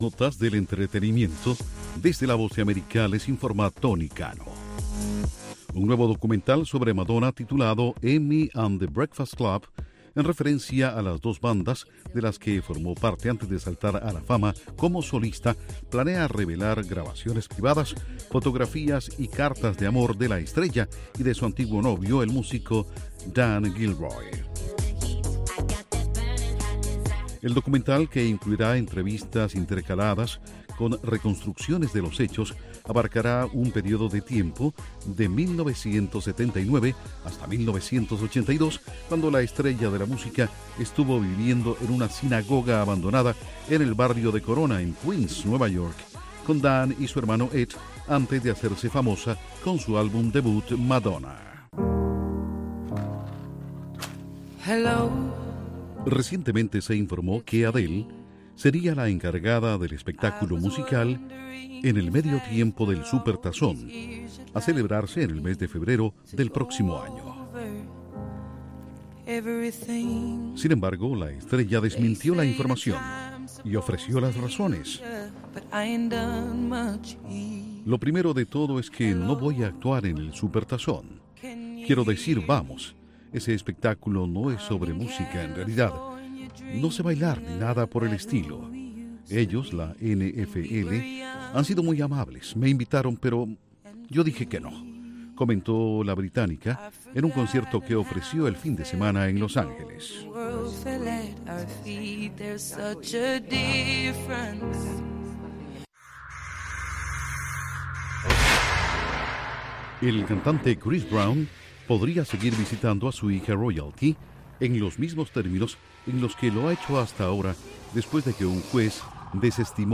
Noticias del entretenimiento - 5:30pm